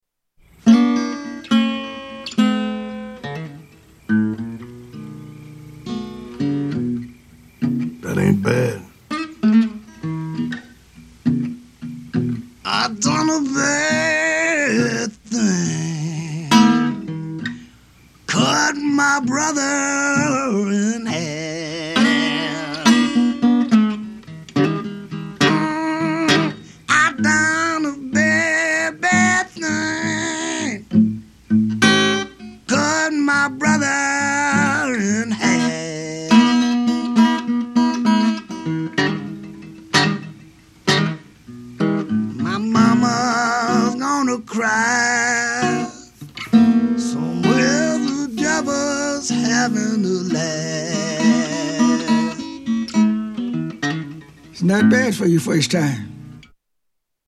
Musical Comedy